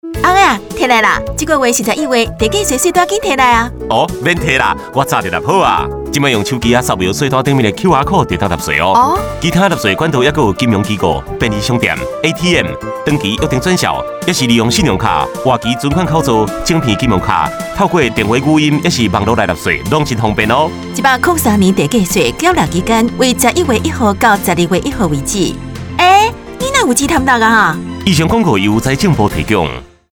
廣播:地價稅開徵宣導短片台語 短片:地價稅開徵宣導短片台語
地價稅宣導30秒廣播廣告-台語.mp3